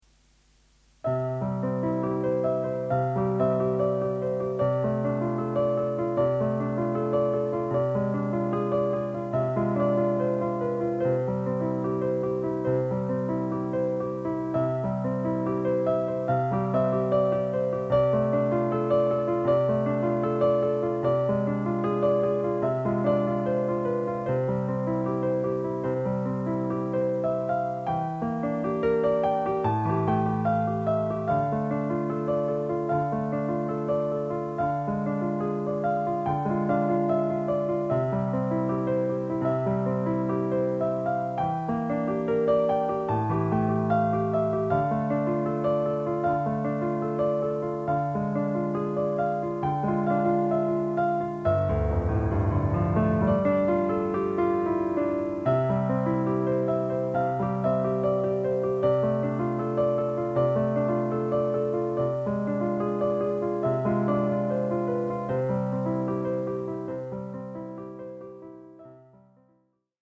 流れるような分散和音が春の風のようです。そして夢見るようなメロディ。